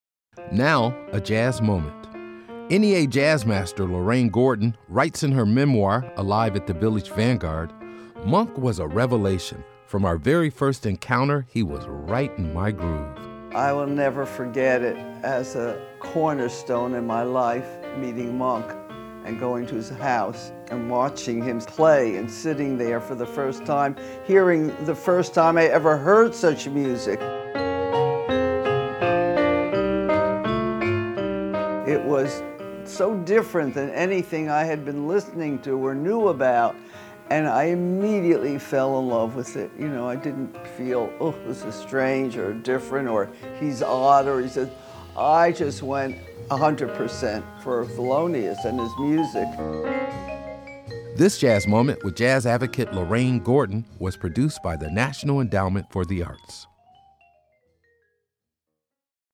MUSIC CREDIT: Excerpt of "Misterioso" composed by Thelonious Monk from the Complete Blue Note Recordings: 1947-1952, used by courtesy of EMI Capitol and used by permission of the Thelonious Monk Corp. (BMI).